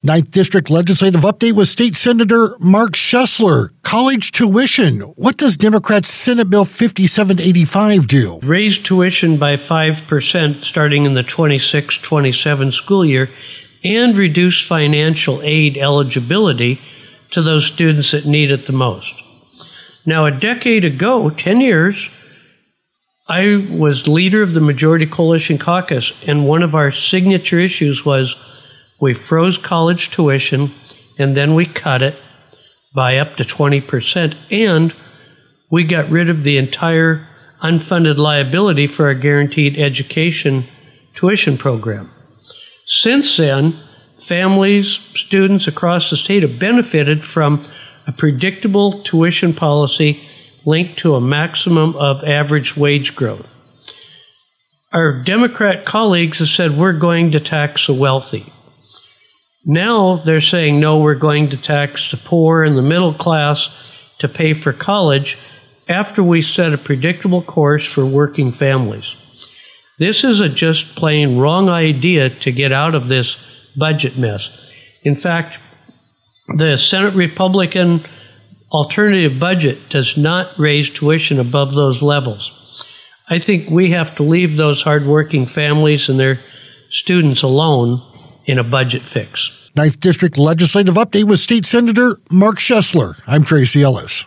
Legislative-Update-with-Senator-Mark-Schoesler-9.mp3